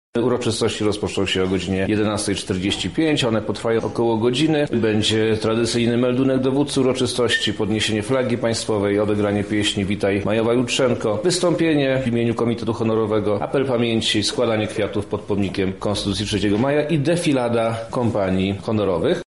O dalszej części obchodów, które odbędą się na Placu Litewskim, mówi Wojewoda Lubelski Przemysław Czarnek: